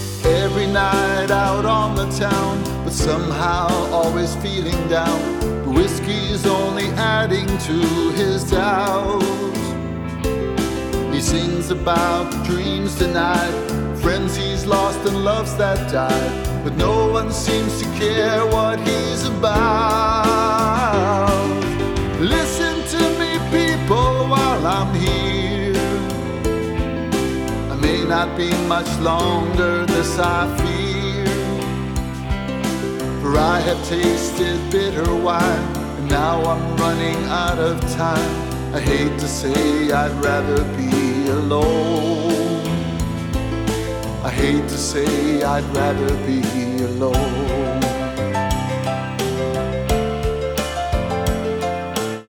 A. Vocal Compositions